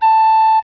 Here are some pipe sound samples randomly collected from volunteering MMD subscribers. They are generally resampled into 11.025 kHz. In several cases it was also appropriate to edit, gain adjust, and/or 20 Hz highpass filter to remove a DC component from the signal.
Open flue pipes
Typical features: Relatively smooth spectrum envelope. A minimum in the envelope at about f0*L/W where f0 is the fundamental frequency, L and W are length and diameter.
Resonator 157*15 mm. Mouth and flue 15*6.5*0.25 mm. Roll 34*10 mm.